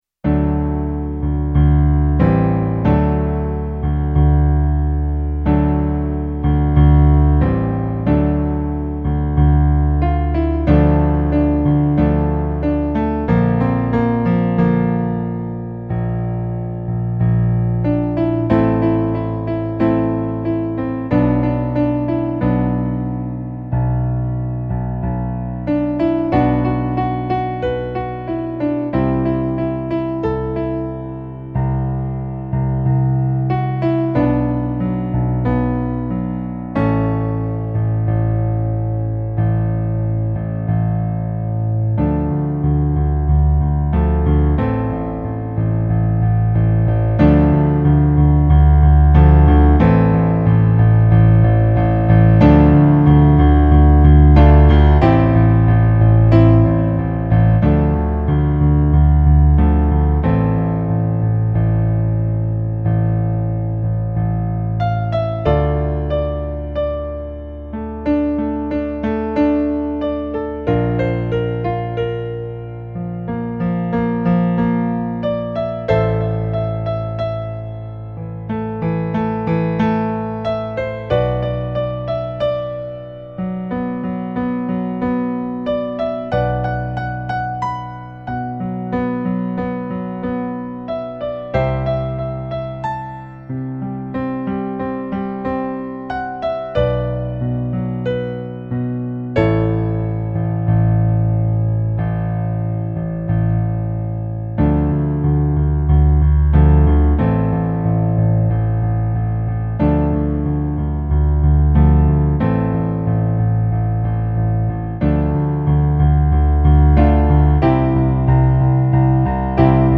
contains eight piano solo arrangements.
African remix